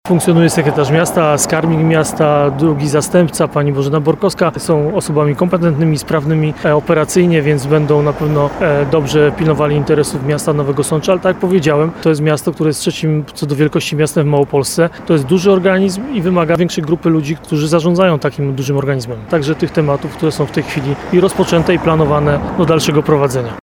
Ludomir Handzel nie pojawił się w środę (29.10) w pracy, ale o swojej decyzji poinformował w rozmowie z Radiem RDN Nowy Sącz.